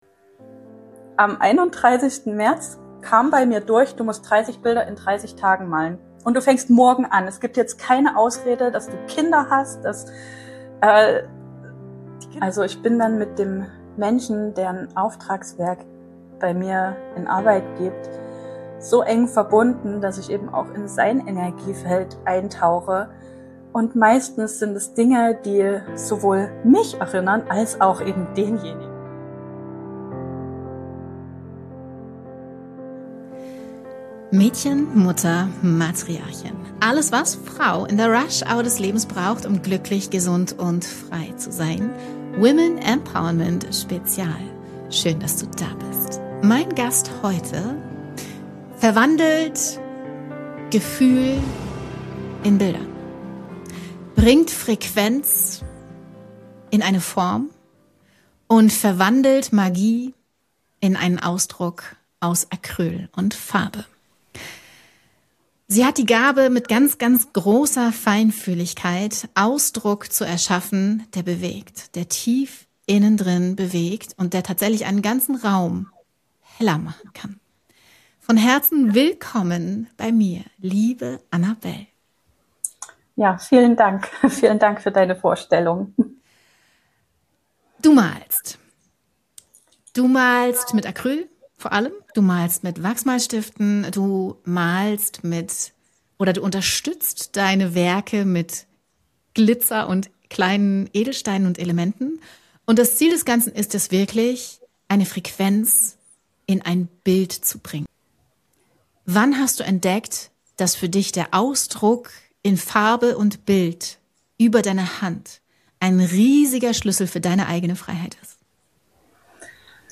In diesem Interview erfährst du: Warum du kein Talent brauchst, um kreativ zu sein Wie intuitives Malen dein Nervensystem beruhigt Warum Bilder Erinnerungen an deine Seele sein können Wie kreative Räume Heilung ermöglichen Warum 2026 das Jahr deiner Strahlkraft ist Dieses Gespräch richtet sich besonders an feinfühlige, ambitionierte Frauen in der Rush Hour des Lebens, die sich nach Tiefe, Wahrhaftigkeit und echter Selbstverbindung sehnen. Kreativität, Intuition, weibliche Strahlkraft, Selbstheilung und energetisches Malen – in diesem Women Empowerment Spezial sprechen wir über die Kraft, durch Farbe und Bild wieder zu dir selbst zu finden.